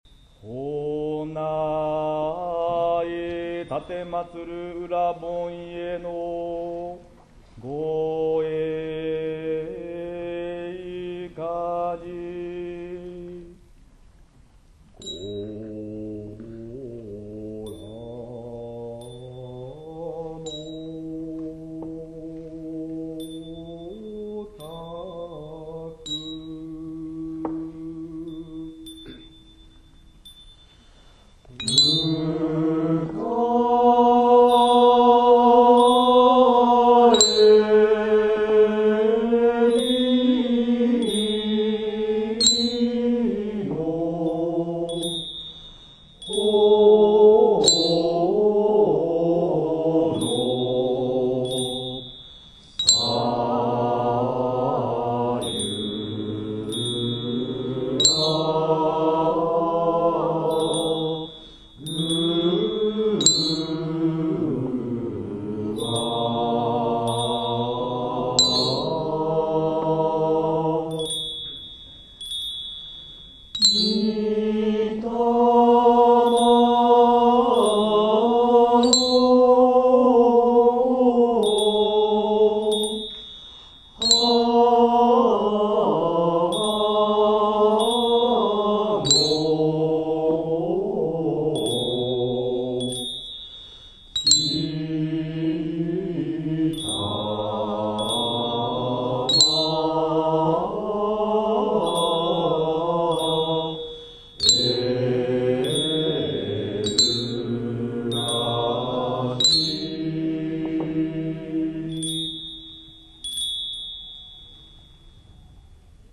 奉詠の始まりは多少声が小さかったものの、会員全員が大きな声で元気よくお唱えして、沢山の拍手をいただくことができました。
♪本番でお唱えしました音源です♪